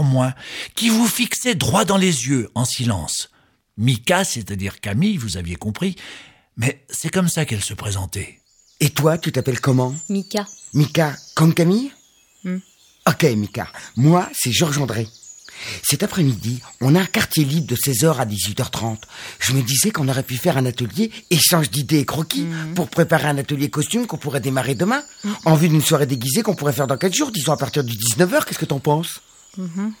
Livre CD